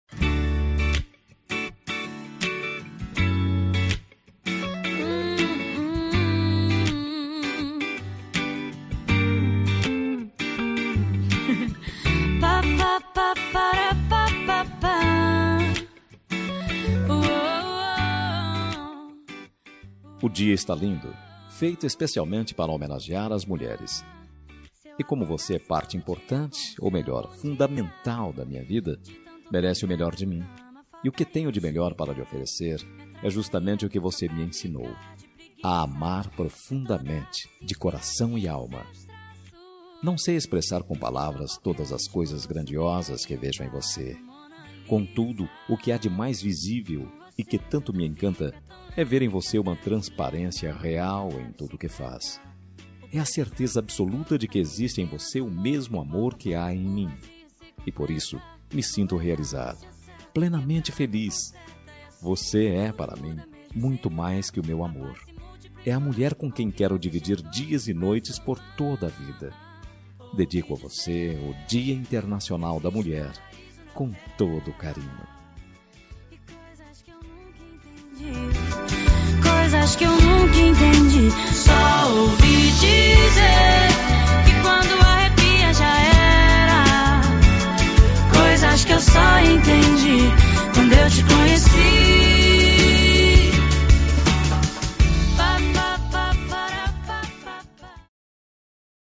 Dia das Mulheres Para Namorada – Voz Masculina – Cód: 53040